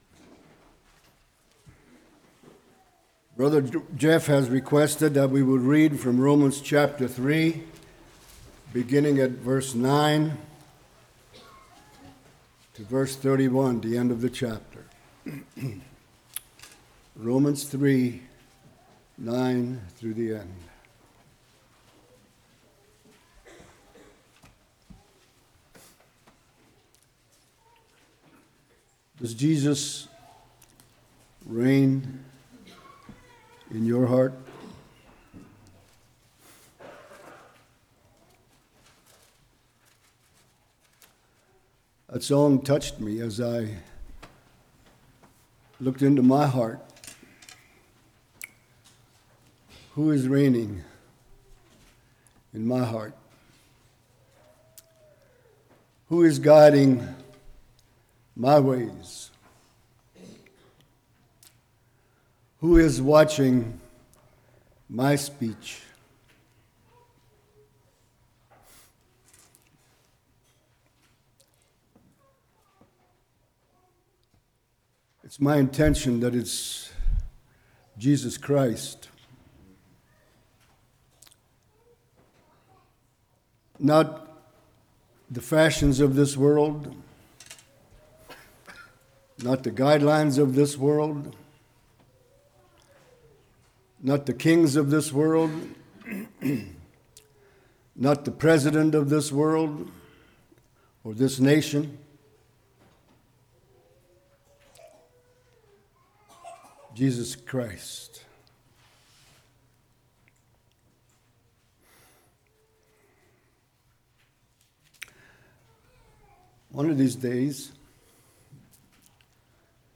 Romans 3:9-31 Service Type: Morning What is sin?